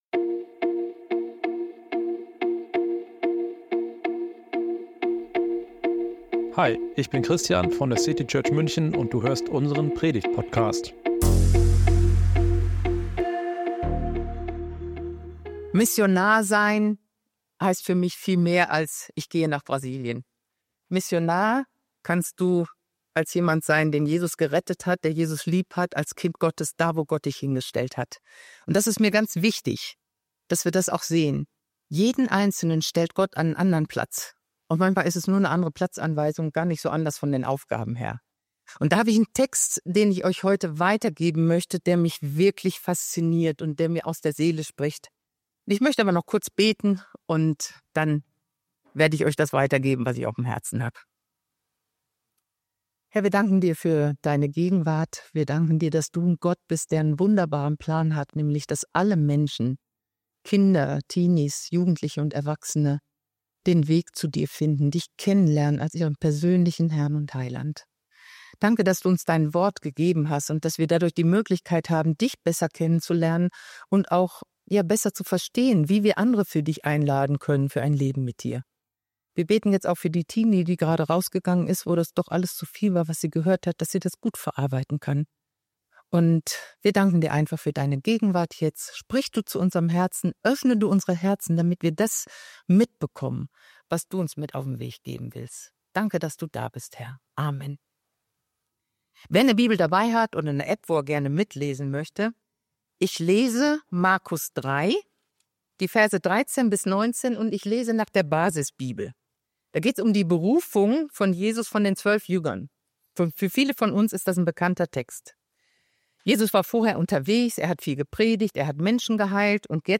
Missionsgottesdienst